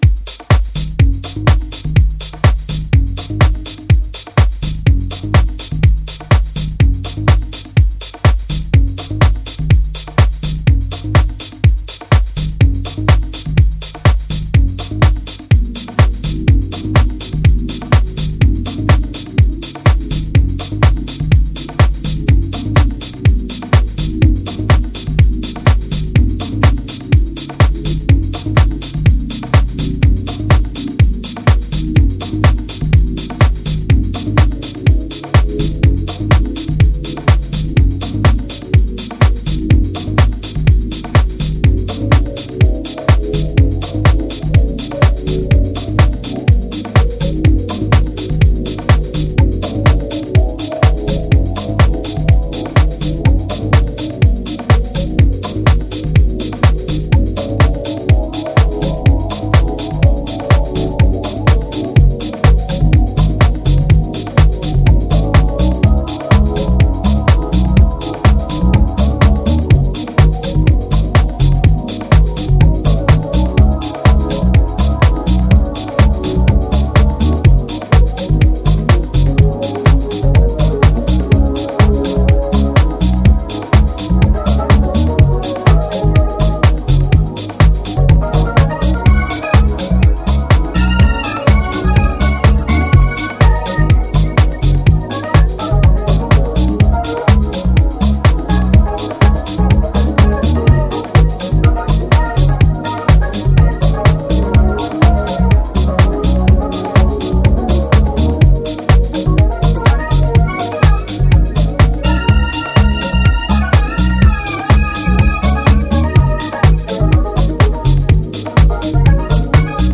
is a supple, subtle and smoky deep house gem for cosy vibes